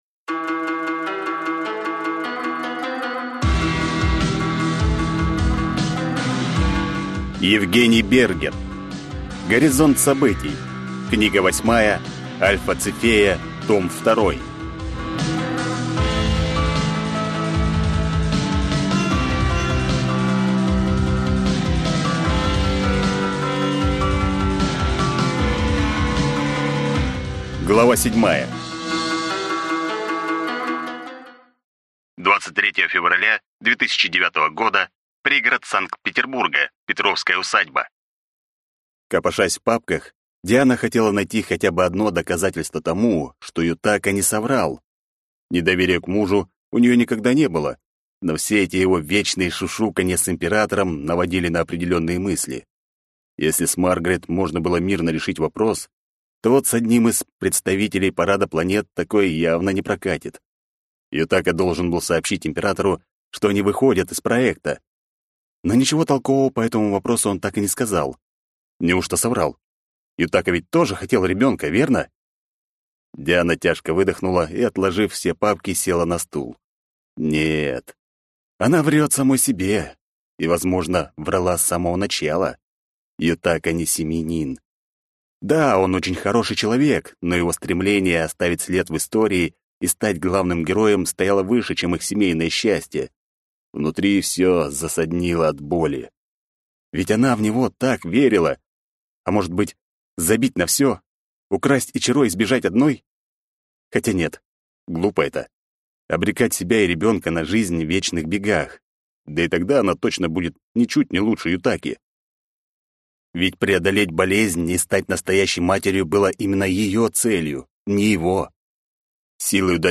Аудиокнига Горизонт событий. Книга 8. Альфа Цефея. Том 2 | Библиотека аудиокниг